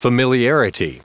Prononciation du mot familiarity en anglais (fichier audio)
familiarity.wav